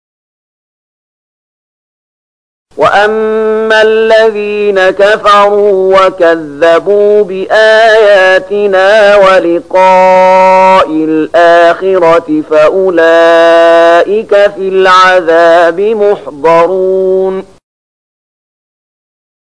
030016 Surat Ar-Ruum ayat 16 dengan bacaan murattal ayat oleh Syaikh Mahmud Khalilil Hushariy: